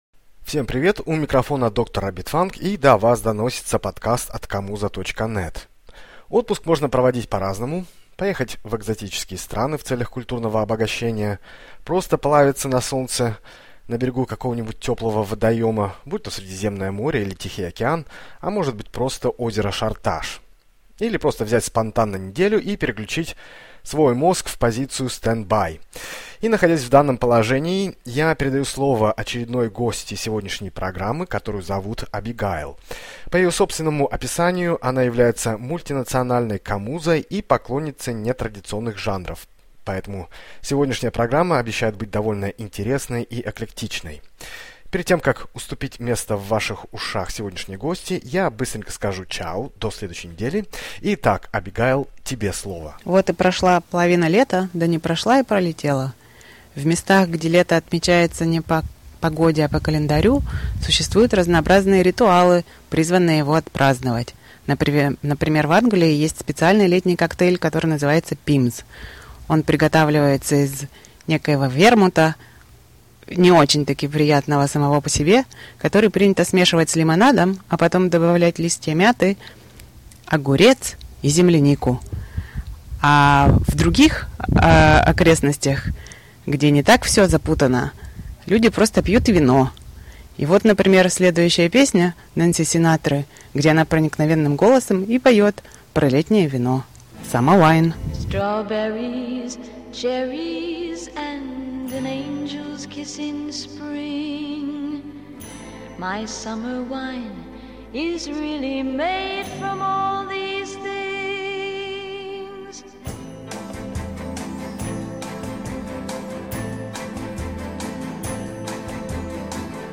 с легким сексуальным акцентом о том, что, чем трясти и под что надо пить этим летом. От Вудстока до Большого Лебовски – наше все.